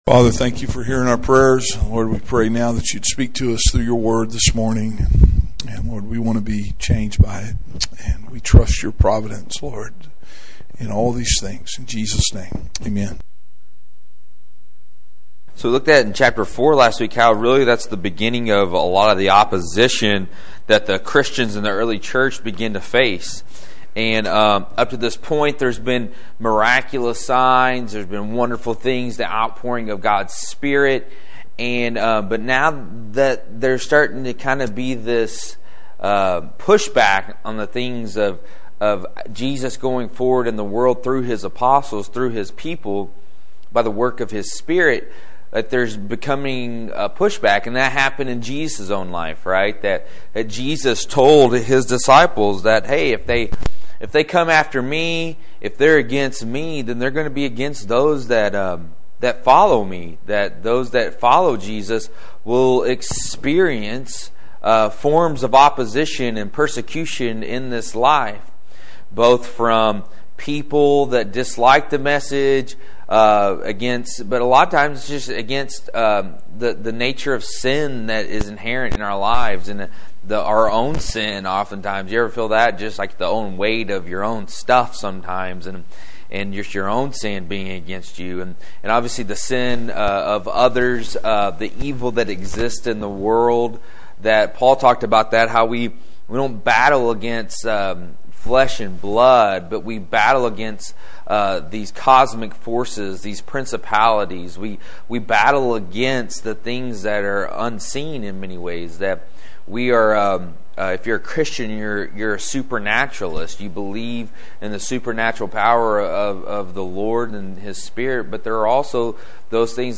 5/10/15 – Adult Bible Study